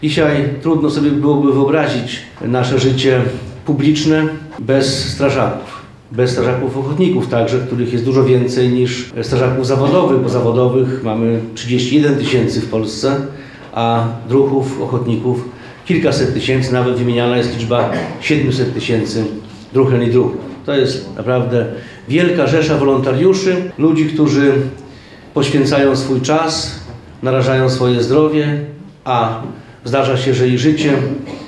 We wtorek (09.10.18) w jednostce Państwowej Straży Pożarnej w Suwałkach przedstawiciele miejscowych samorządów podpisali oficjalne umowy w tej sprawie. Podczas spotkania głos zabrał między innymi Jarosław Zieliński, sekretarz stanu w Ministerstwie Spraw Wewnętrznych i Administracji, który podkreślał rolę druhów.